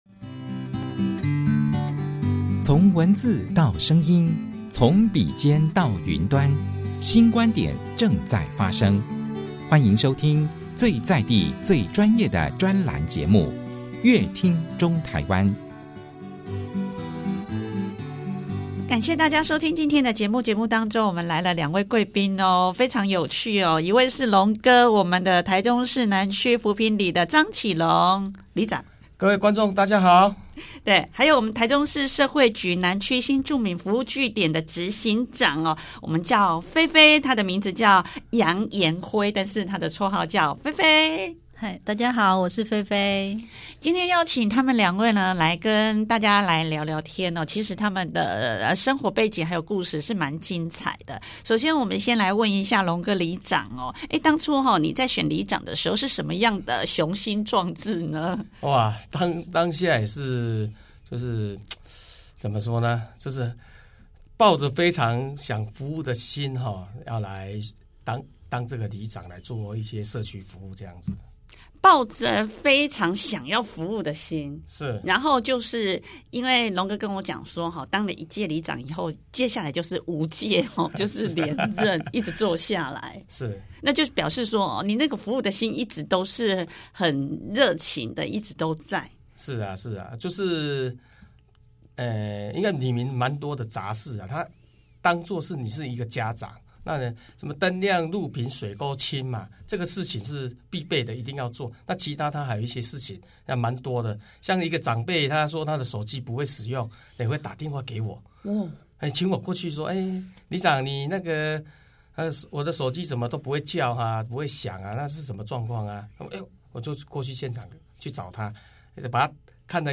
想知道更多精彩的專訪內容，請鎖定本集的節目。